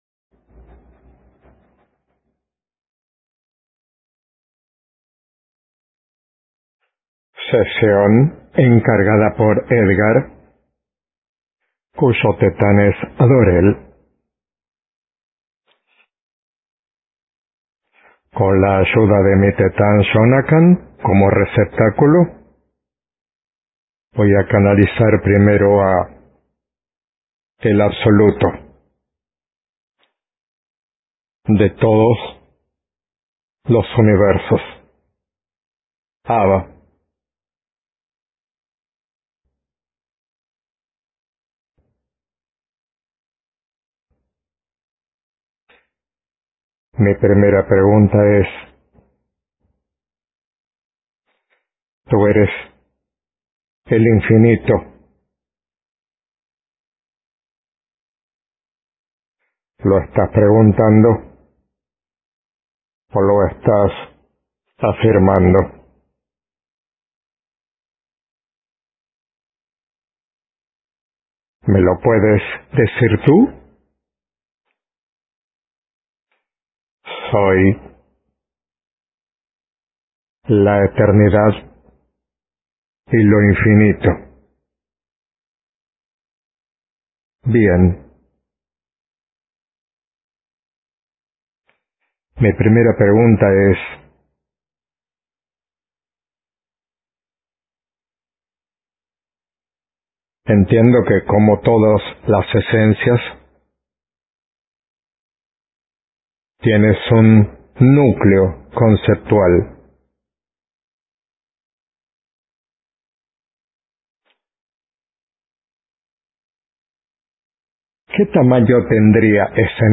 Sesión del 05/08/2008 Sesión del 06/06/2009 Sesión del 07/10/2022 Sesión del 30/01/2023 Sesión del 02/02/2026 Sesión del 5/8/08 Médium